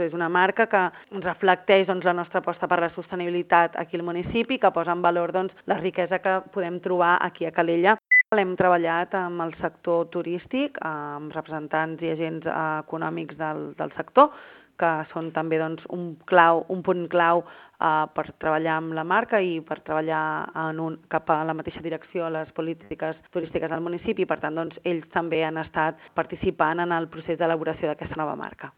La tinent d’alcaldia de Turisme, Cindy Rando, ha explicat a Ràdio Calella TV que la nova identitat vol simbolitzar aquesta aposta estratègica.